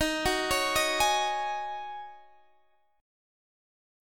Ebm11 Chord
Listen to Ebm11 strummed